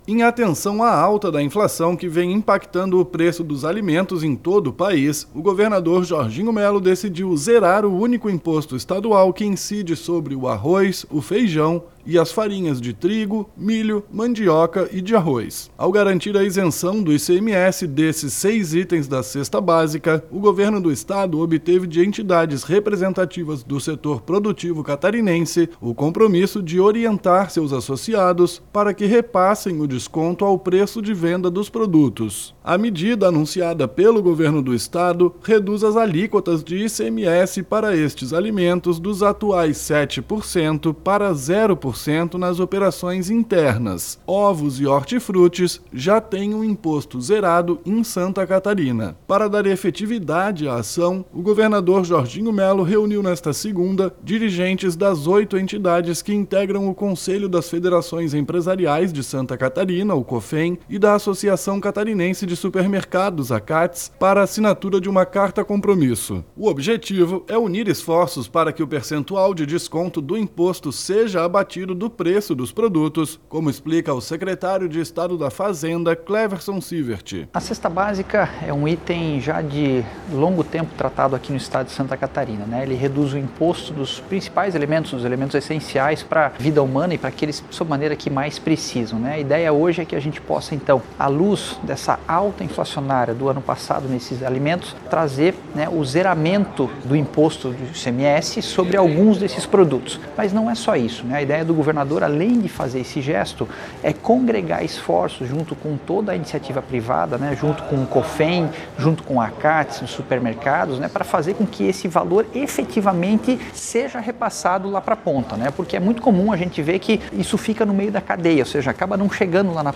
BOLETIM – Governador zera imposto de arroz, feijão e farinhas e garante apoio de entidades para que desconto chegue ao consumidor
O objetivo é unir esforços para que o percentual de desconto do imposto seja abatido do preço dos produtos, como explica o secretário de Estado da Fazenda, Cleverson Siewert: